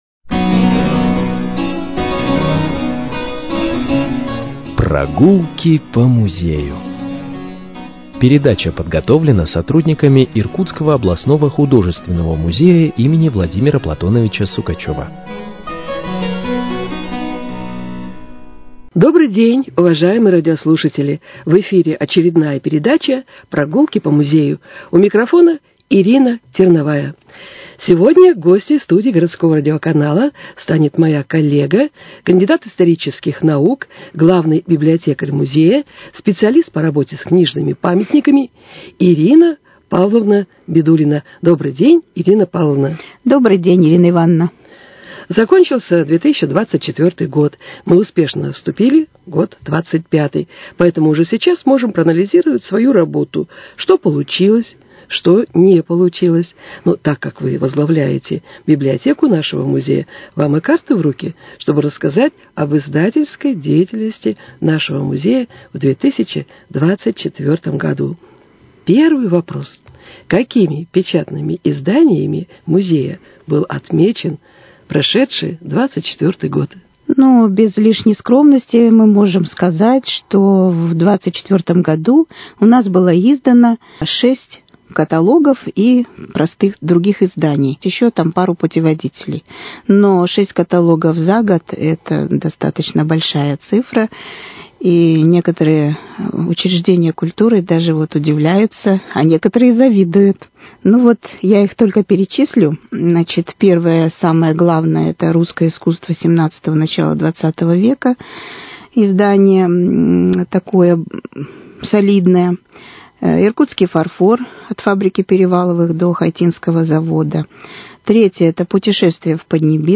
Прогулки по музею: Беседа
Передача